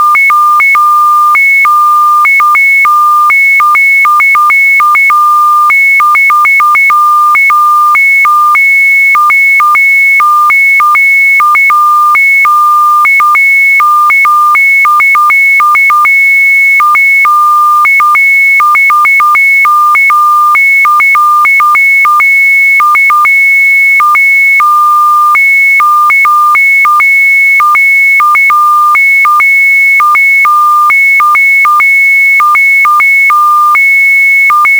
It’s just noise.